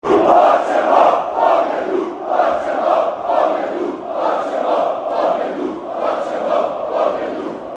Eksplodirao je sjever, eksplodirao je Maksimir, a s tim se rezultatom otišlo na poluvrijeme.
BBB su ispoštivali minutu šutnje te odličnim navijanjem bodrili Modre do zadnje minute.